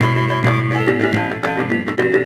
Percussion 22.wav